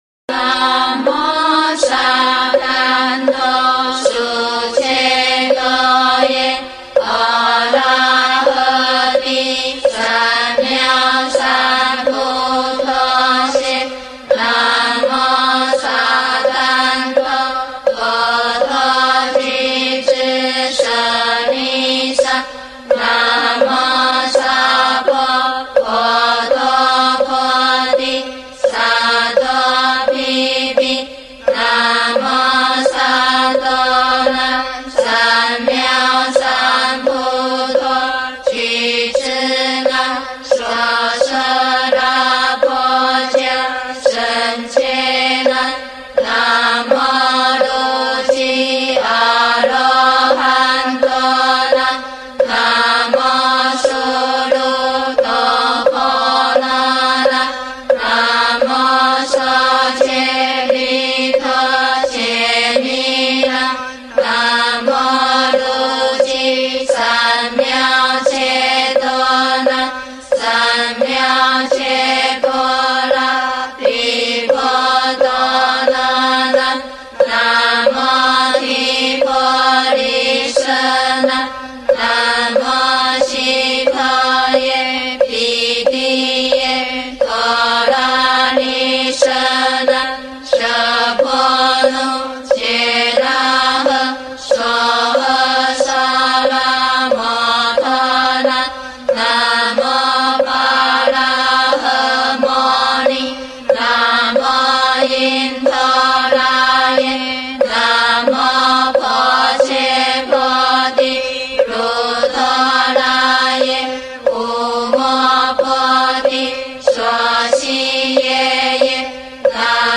楞严咒完整版念诵，庄严殊胜。万佛城版本，清晰标准，适合学习持诵。